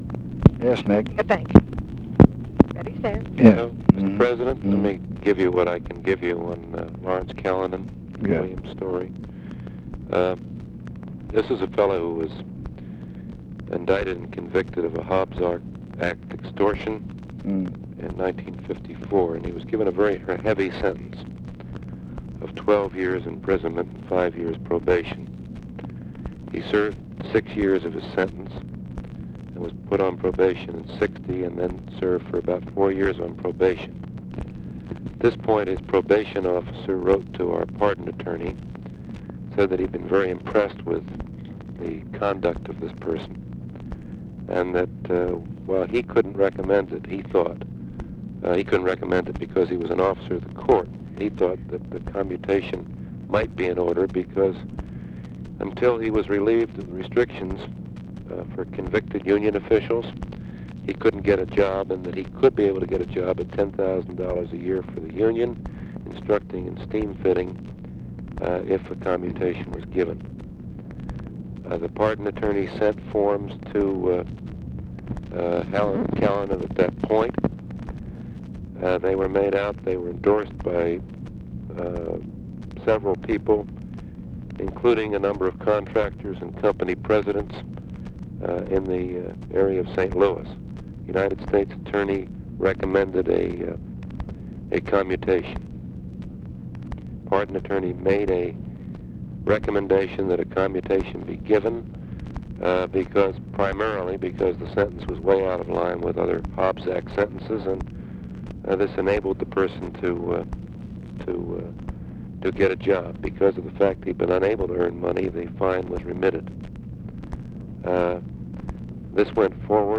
Conversation with NICHOLAS KATZENBACH, January 20, 1966
Secret White House Tapes